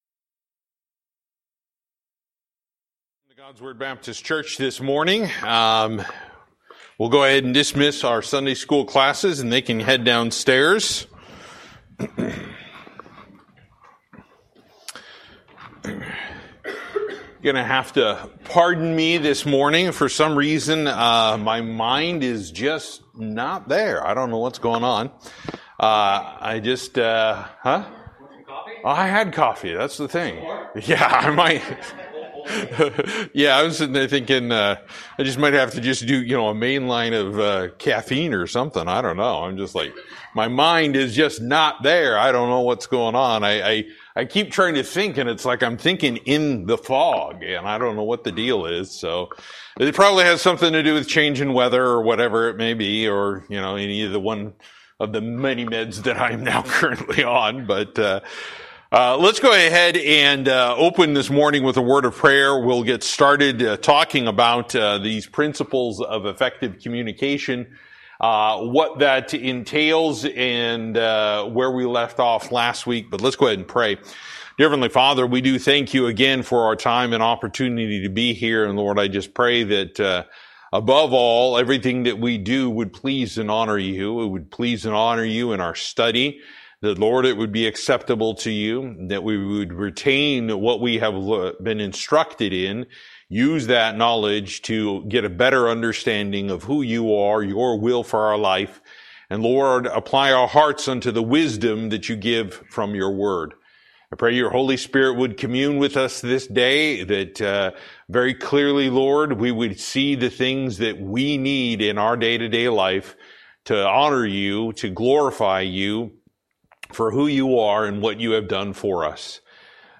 Service: Sunday School